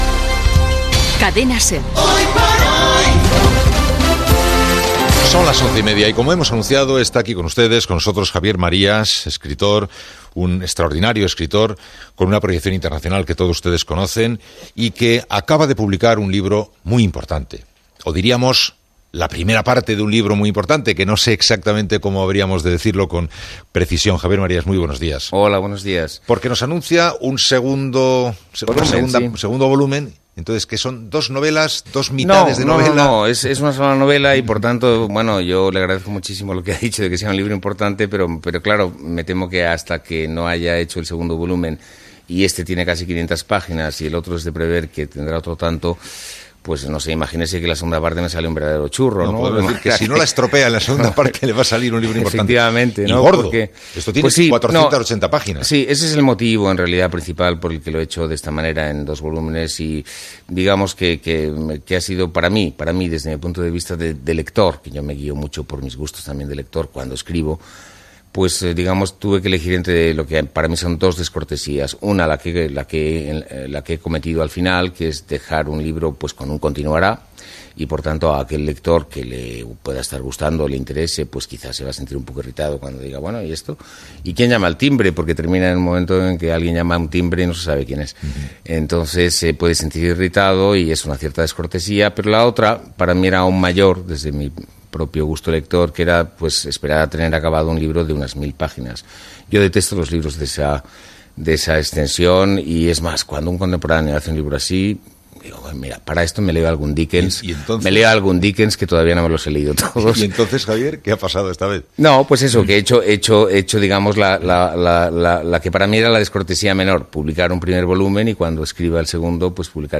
Indicatiu del programa, presentació i entrevista a l'escriptor Javier Marias que ha publicat "Tu rostro mañana" el primer volum de "Fiebre y Lanza"
Info-entreteniment